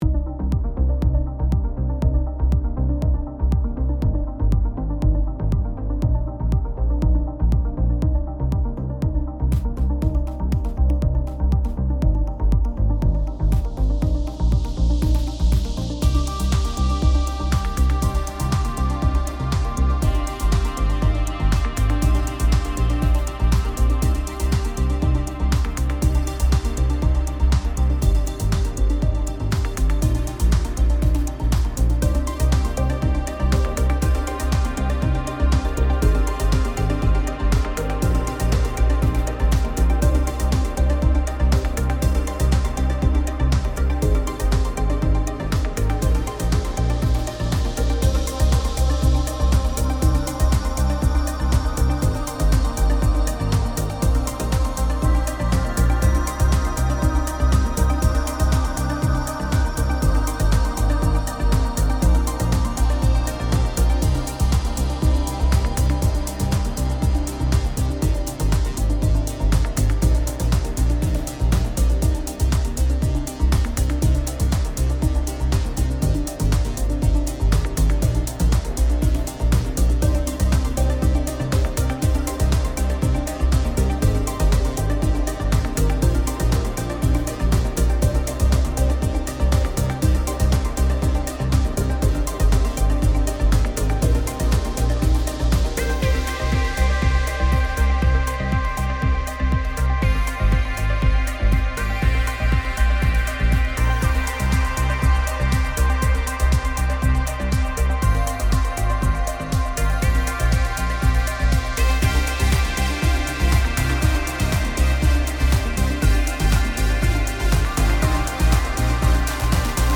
Ambient Chill Out / Lounge Cinematic / FX Minimal Multi-genre Synthwave / Retrowave Trance
Speed Racer gives you a huge arsenal of powerful sounds at your fingertips. 20 Arps and 8 sequences to get your blood pumping. 72 drums and percussion to ramp up the groove beats in your tracks to let your music feel the power and movement it deserves. 23 pads and 9 FX to balance the feel and mood of your story telling. 16 keys, 9 leads and 20 plucks to fill in the gaps and move your songs in for those melody kills. 21 basses from heart pounding to silky smooth rides and 5 synthetic voxes to hum a long with.
All drums, percussion and choirs found in the Speed Racer library presets audio/video demos are included in the Speed Racer library presets pack for Spire.